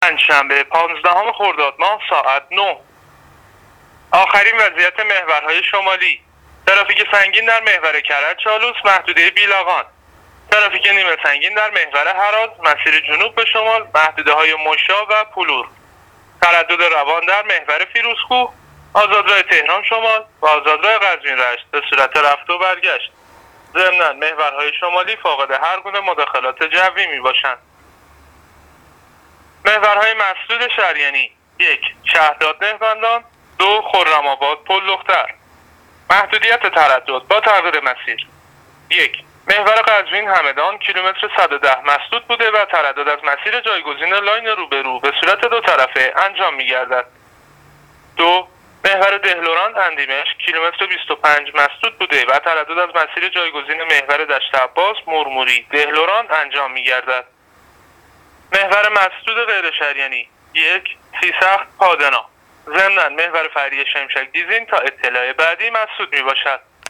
گزارش رادیو اینترنتی از وضعیت ترافیکی جاده‌ها تا ساعت ۹ پانزدهم خردادماه